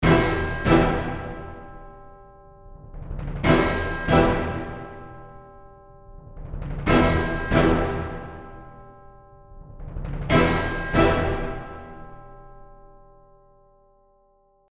You can hear all of these instruments together:
OrchestralHits_WholeOrchestra.mp3